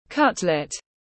Thịt cốt lết tiếng anh gọi là cutlet, phiên âm tiếng anh đọc là /ˈkʌt.lət/
Cutlet /ˈkʌt.lət/